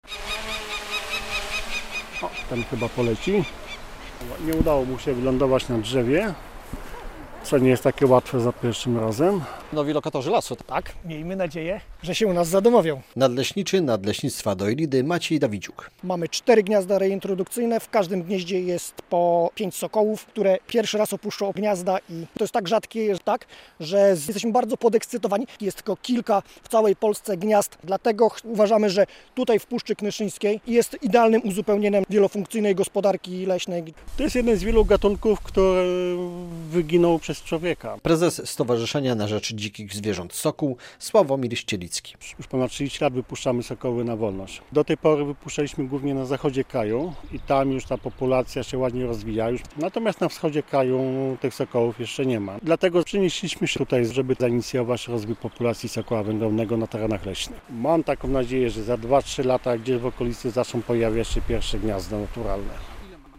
W Nadleśnictwie Dojlidy pojawiły się sokoły wędrowne - relacja